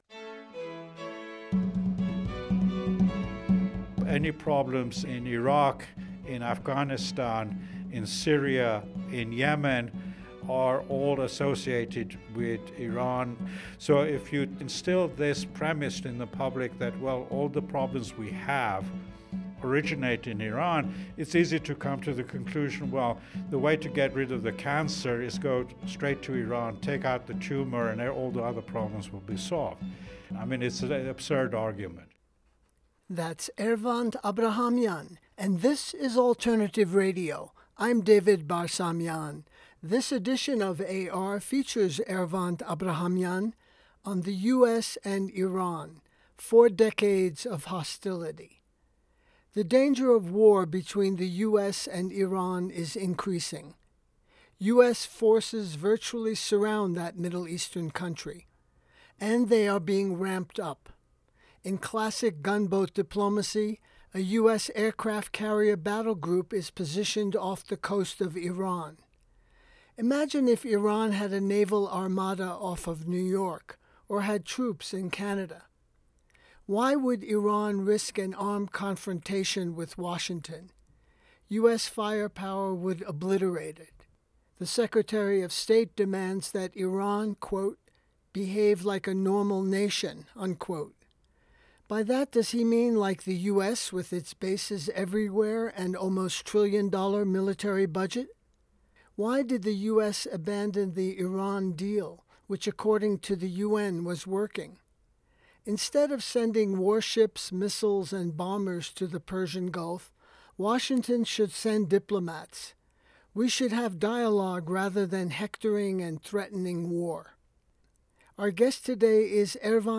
AbrahamianInterview-3dB.mp3